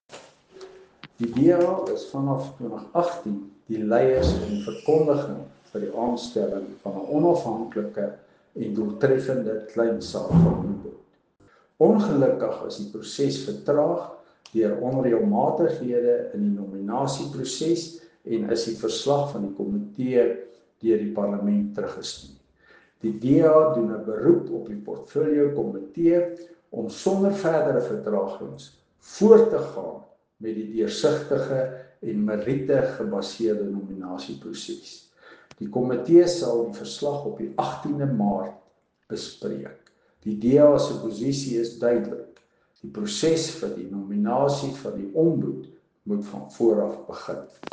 Afrikaans soundbites by Henro Kruger MP.